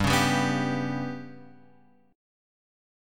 GMb5 chord {3 4 5 4 x 3} chord